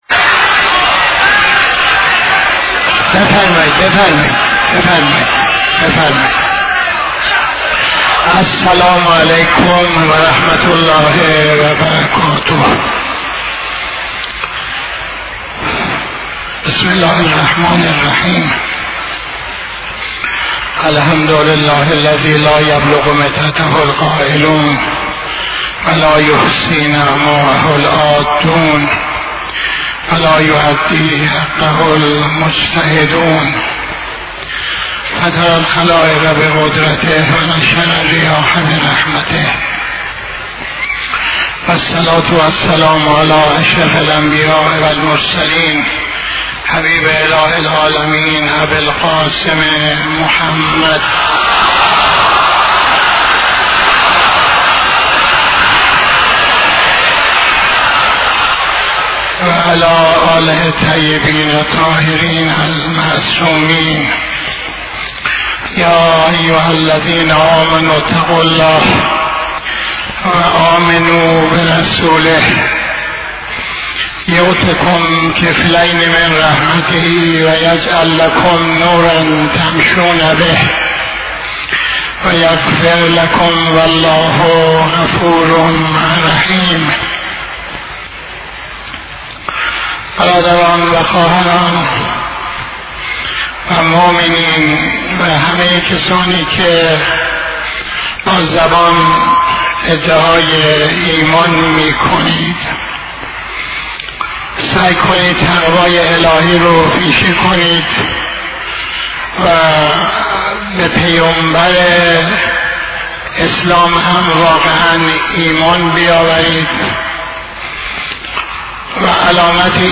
خطبه اول نماز جمعه 14-12-84